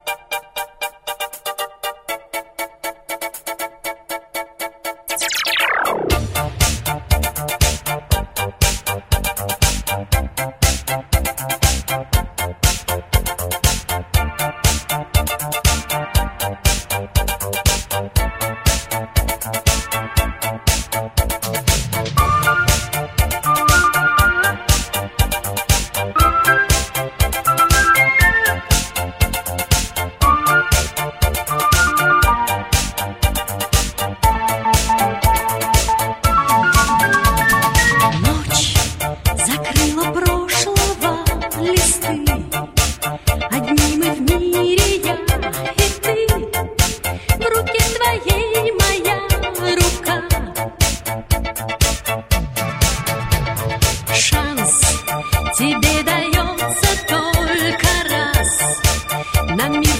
Pop
диско-группы